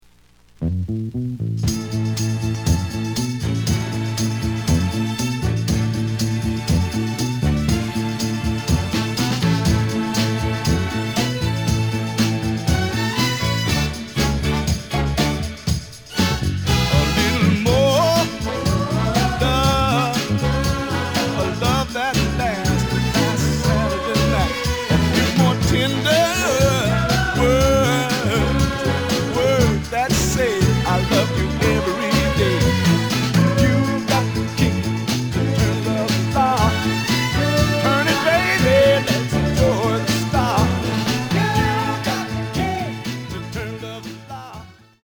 The listen sample is recorded from the actual item.
●Genre: Soul, 60's Soul